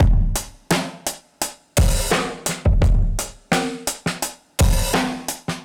Index of /musicradar/dusty-funk-samples/Beats/85bpm/Alt Sound
DF_BeatB[dustier]_85-04.wav